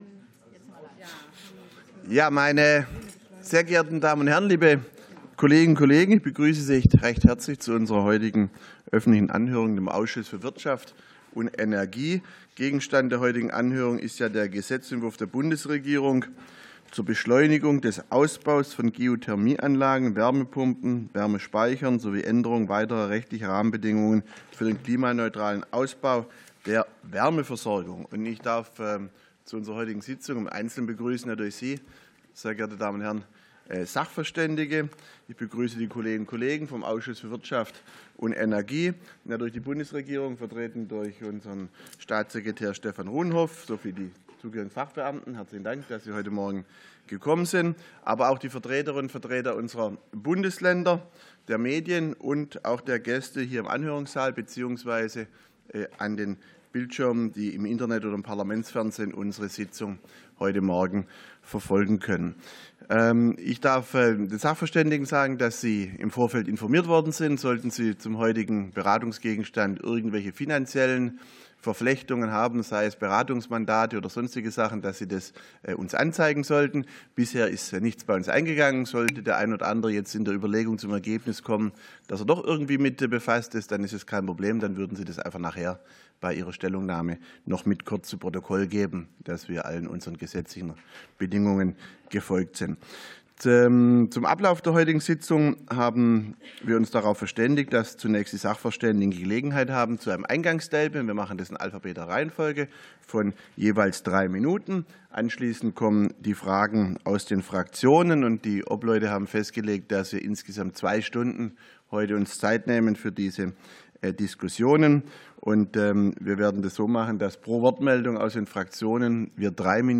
Anhörung des Ausschusses für Wirtschaft und Energie